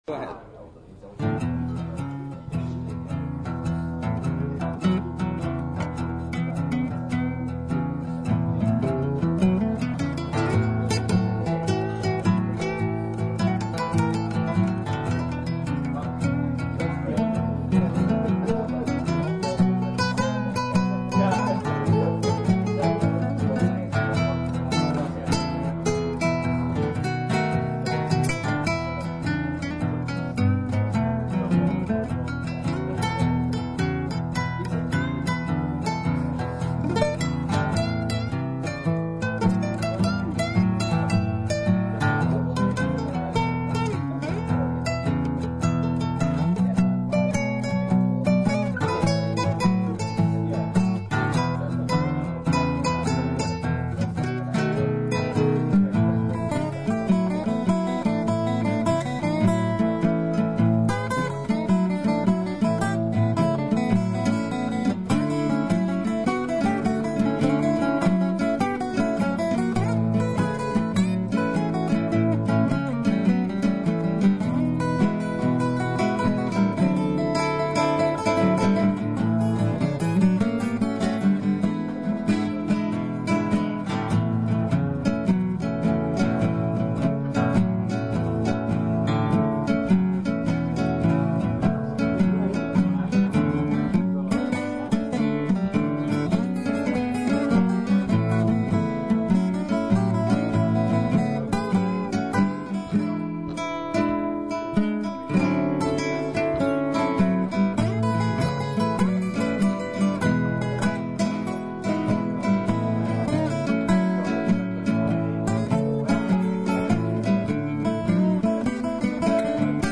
made in the bar of the Hotel Mercure, Potsdam, August 22nd, and August 24th, 2006.
12-bar Blues in E [2:28]
12 bar blues in E.mp3